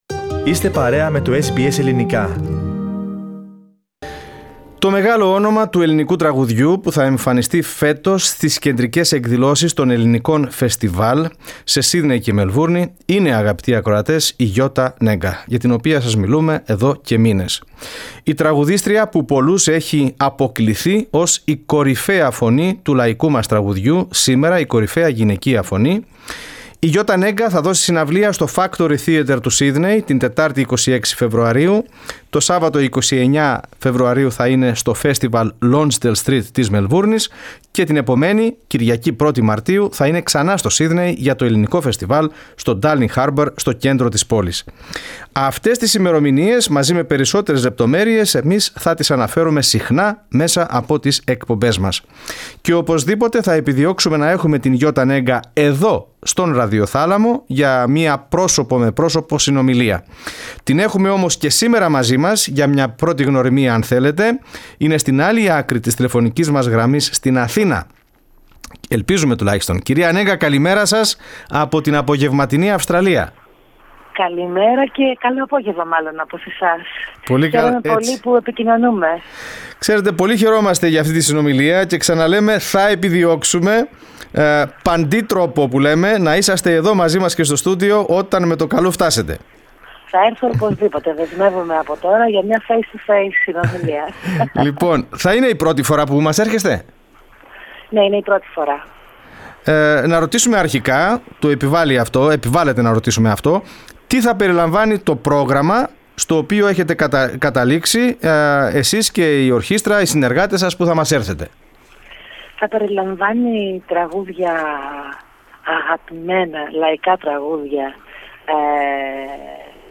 Η Γιώτα Νέγκα, ένα από τα μεγάλα ονόματα του ελληνικού λαϊκού – και όχι μόνο - τραγουδιού θα εμφανιστεί φέτος στις κεντρικές εκδηλώσεις των Ελληνικών φεστιβάλ σε Σίδνεϊ και Μελβούρνη. Το SBS Greek εξασφάλισε μια πρώτη «γνωριμία» με την καλλιτέχνιδα, με συνέντευξη που μας παραχώρησε ζωντανά στον «αέρα» της εκπομπής λίγες ημέρες προτού αναχωρήσει από την Αθήνα για Αυστραλία.
Πρόγευση από... Ελληνικό Φεστιβάλ με τη Γιώτα Νέγκα live «στον αέρα»!